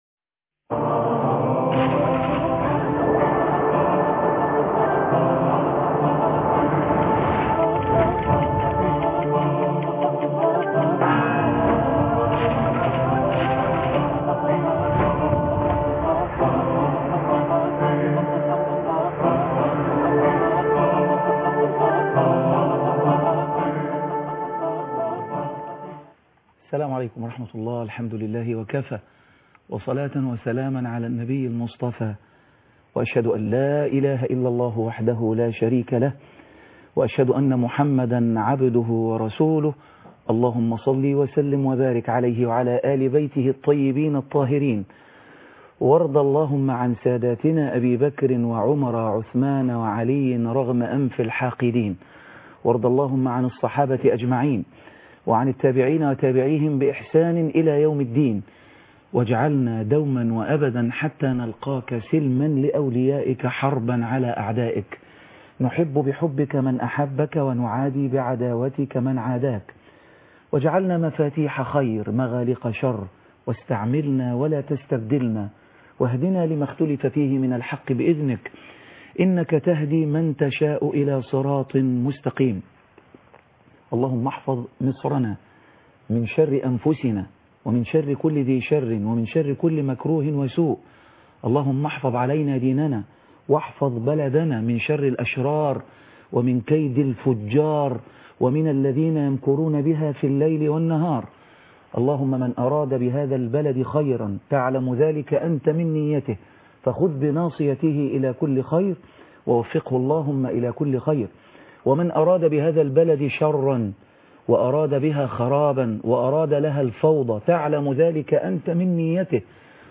مناظرة ولقاء خاص بين